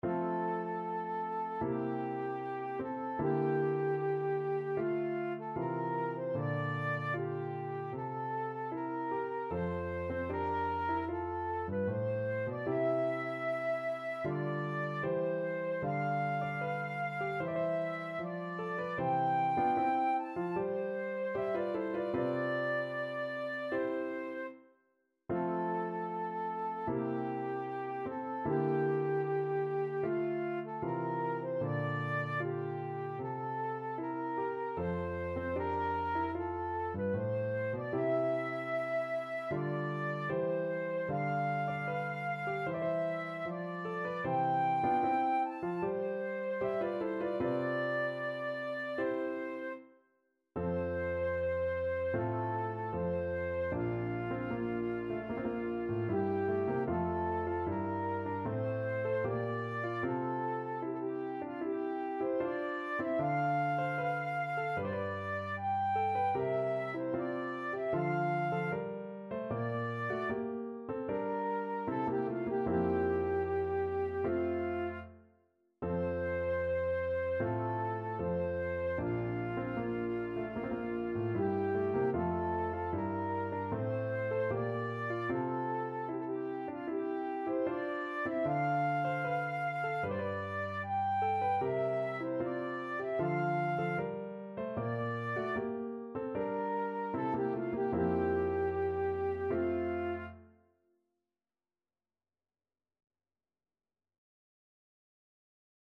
Free Sheet music for Flute
4/4 (View more 4/4 Music)
F major (Sounding Pitch) (View more F major Music for Flute )
Andante =76
Flute  (View more Easy Flute Music)
Classical (View more Classical Flute Music)
march_idomeneo_act3_FL.mp3